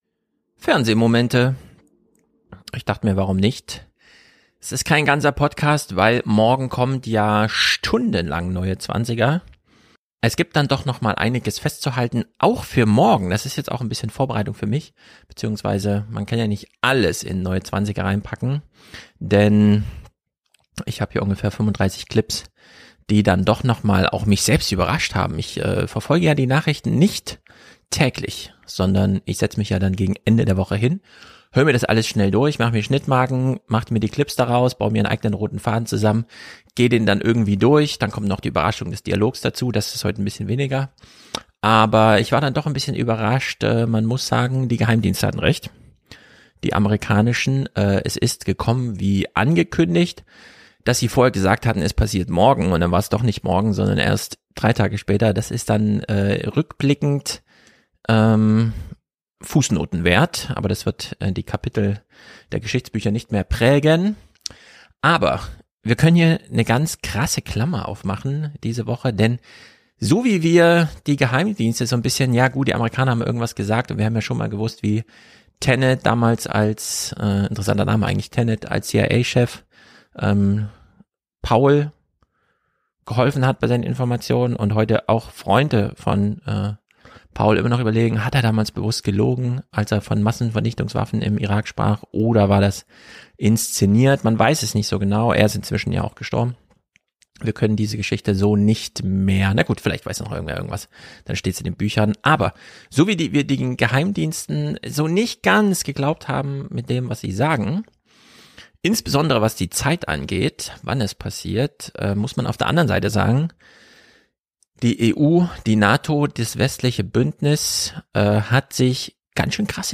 Jetzt sind wir da, im Zeitalter der Hyperpolitik. Wir gehen die Nachrichtenwoche durch und hören eure Audiokommentare.